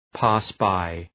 pass-by.mp3